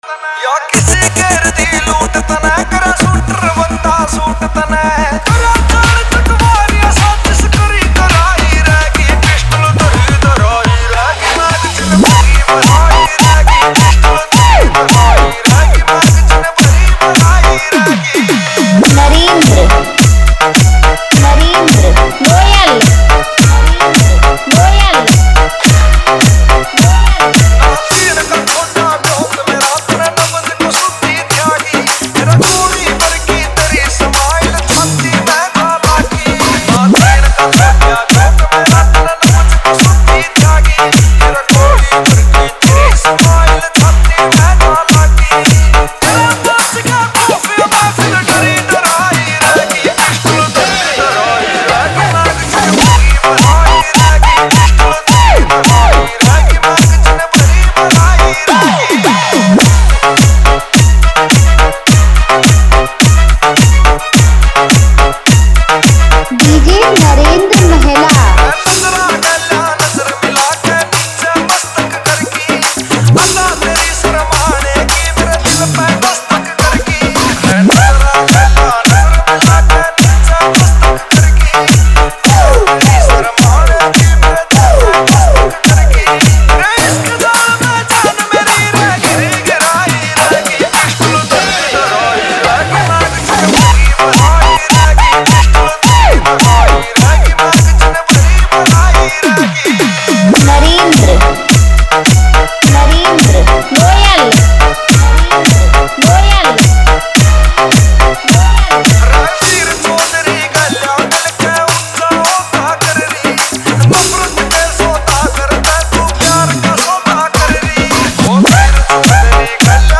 Dj-Remix